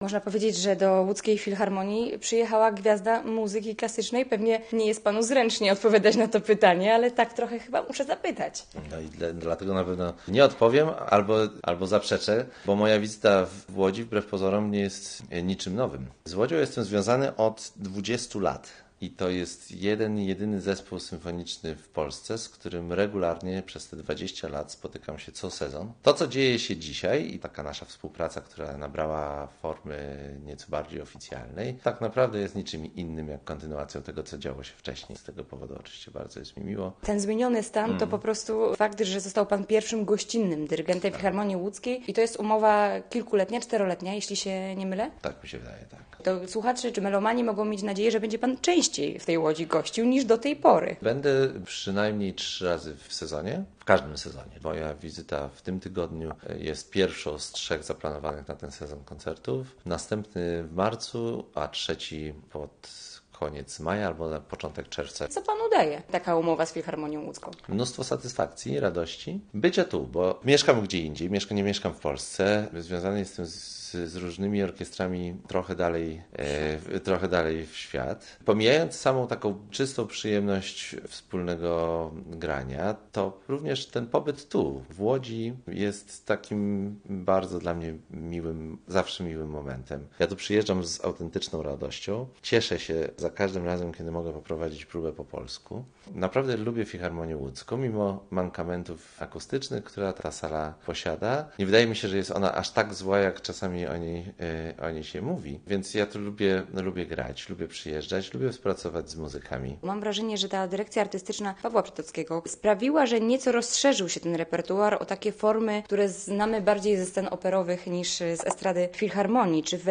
Posłuchaj i dowiedz się więcej: Nazwa Plik Autor Rozmowa z Michałem Nesterowicz audio (m4a) audio (oga) WIĘCEJ INFORMACJIO KULTURALNEJ ŁODZI I REGIONIE .